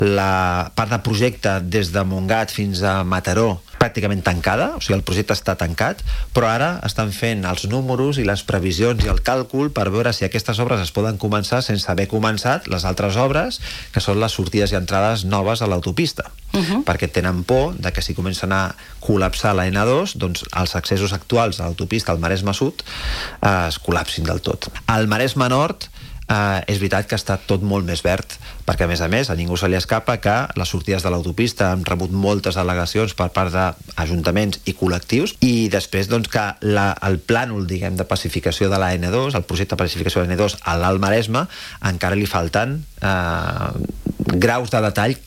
El nou president del Consell Comarcal del Maresme, Rafa Navarro (JUNTS), ha passat pels estudis de Ràdio TV per analitzar els principals reptes de la comarca.